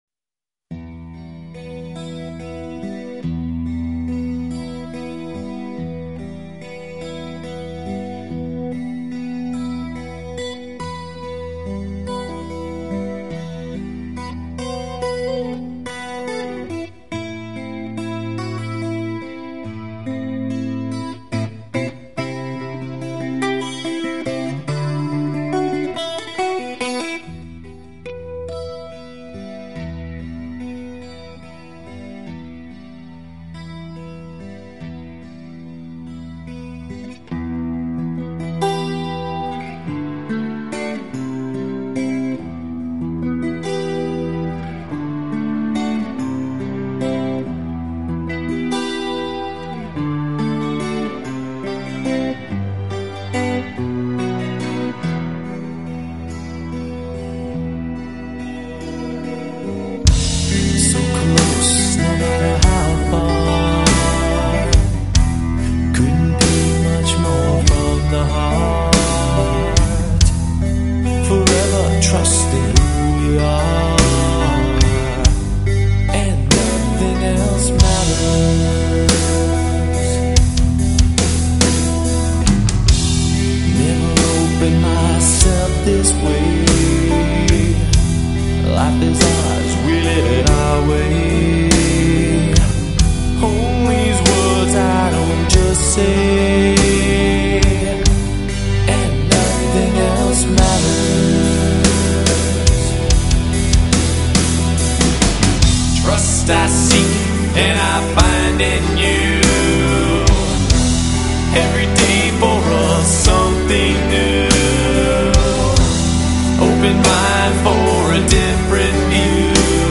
"АКУЛЫ" настоящего рока